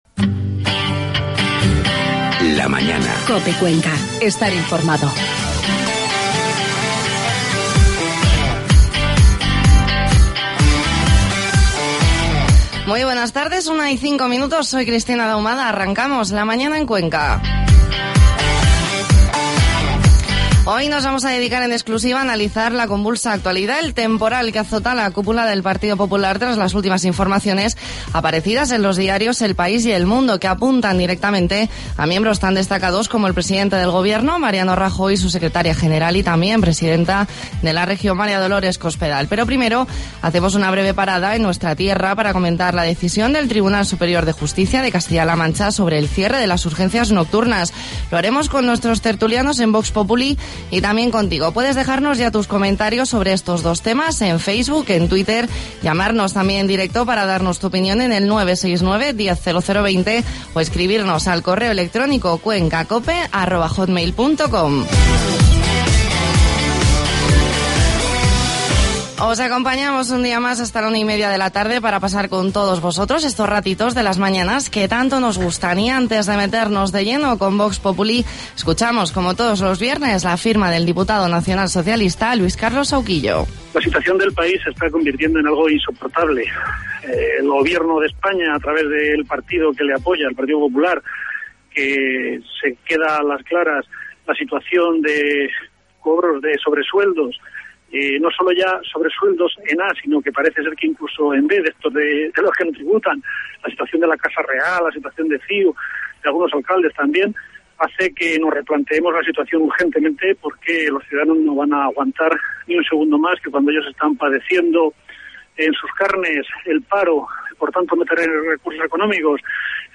Inicio del magazine
tertulia